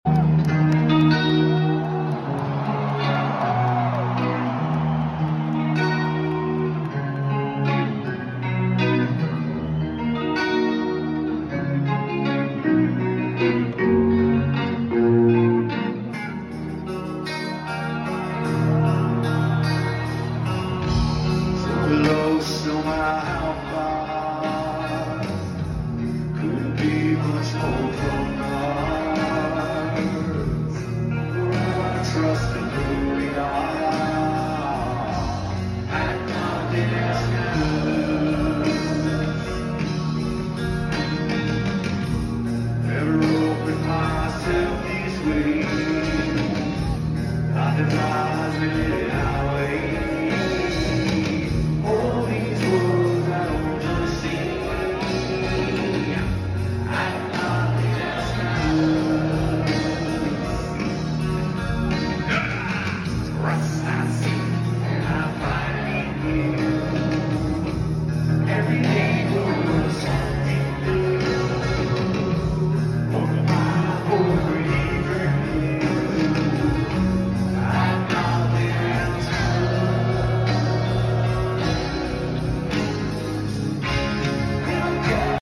Concierto